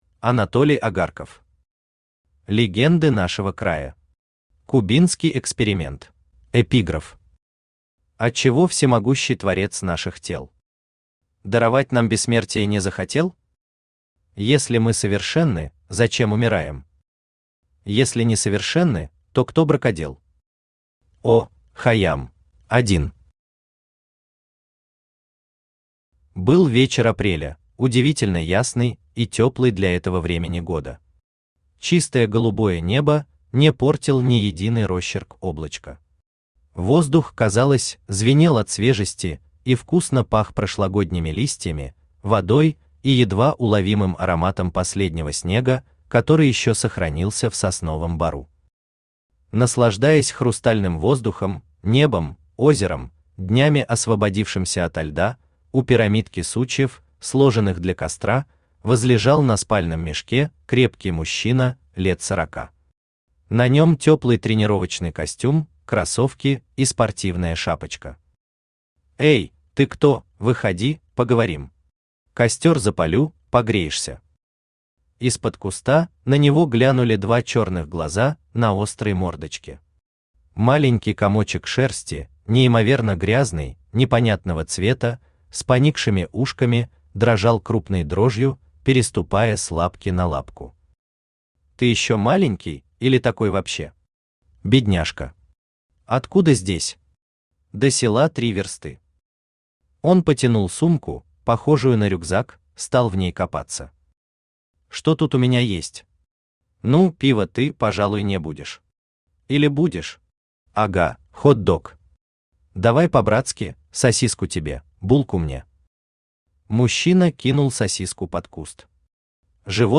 Аудиокнига Легенды нашего края. Кубинский эксперимент | Библиотека аудиокниг
Кубинский эксперимент Автор Анатолий Агарков Читает аудиокнигу Авточтец ЛитРес.